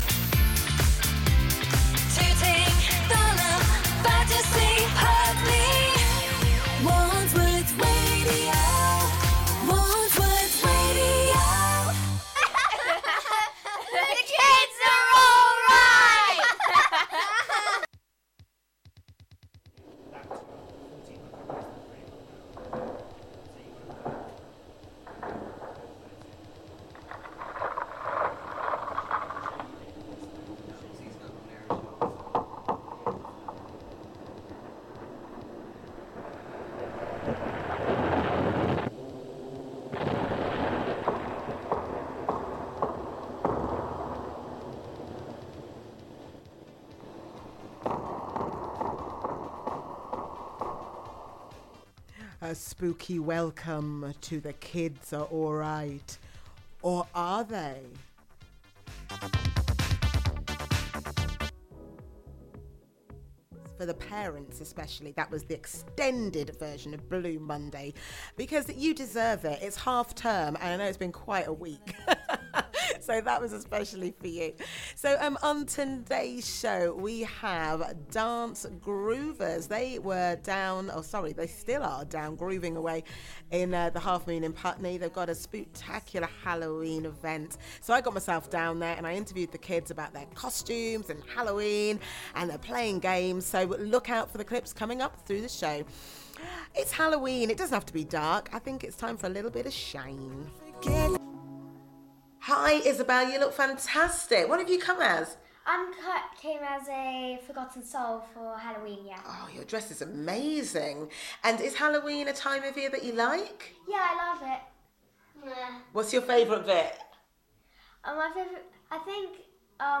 Bumps , screams cackles...and that was just the news reader! Spooky times were had on 30th November with plenty of halloween costumes, jokes, stories and games.